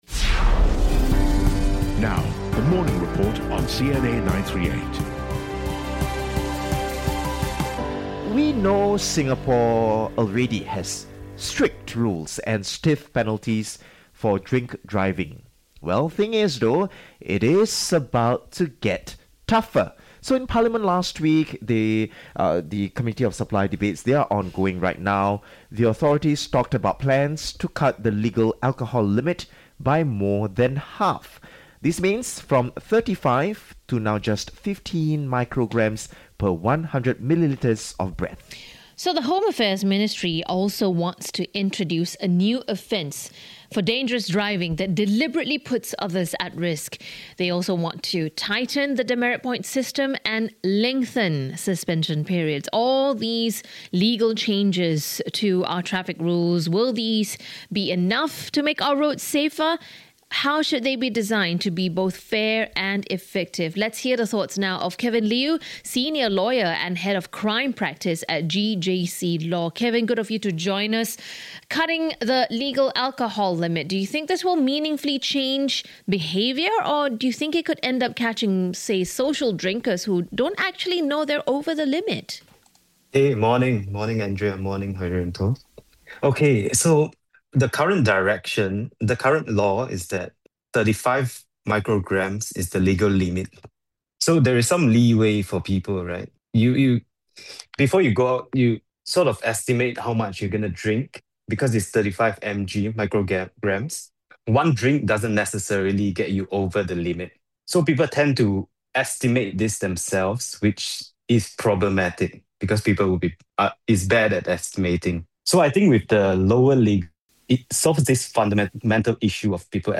In a recent discussion